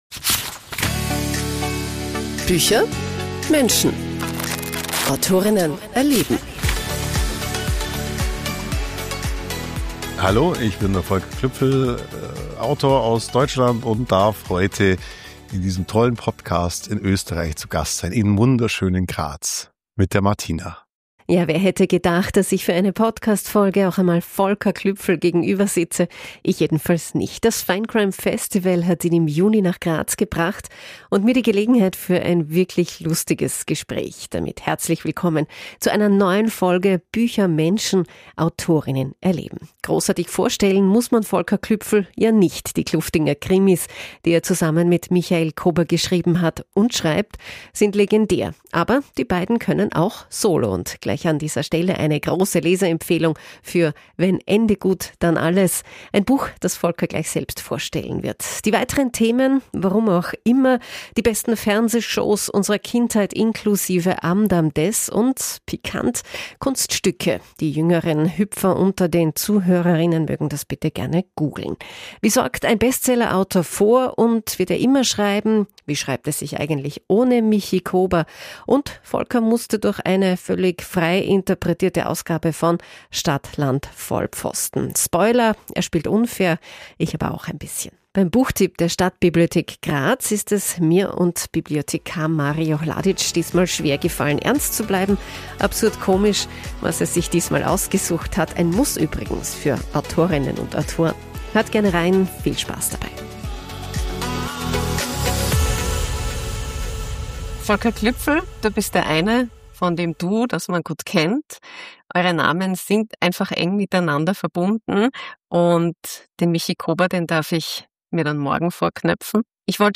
Und gleich an dieser Stelle eine große Leseempfehlung für _Wenn Ende gut dann alles_ ein Buch, das Volker in dieser Folge selbst vorstellen und auch ein Stück daraus vorlesen wird. Die weiteren Themen – warum auch immer :) Die besten Fernsehshows unserer Kindheit inklusive Am Dam Des und - pikant - Kunststücke – die jüngeren Hüpfer unter den Zuhörerinnen mögen das bitte gerne googlen – wie sorgt ein Bestsellerautor vor und wird er immer schreiben – wie schreibt es sich eigentlich ohne Michi Kobr – und Volker musste durch eine völlig frei interpretierte Ausgabe von Stadt Land Vollpfosten – Spoiler: Er spielt unfair.